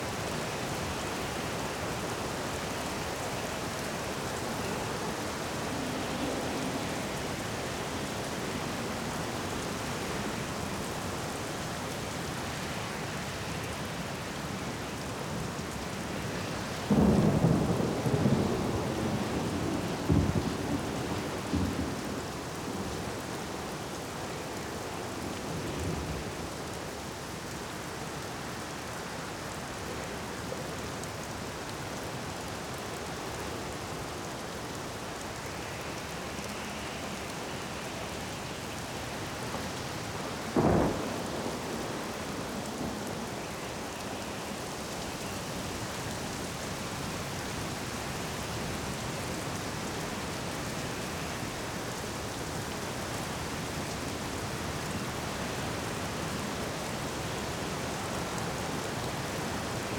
Sea Storm.wav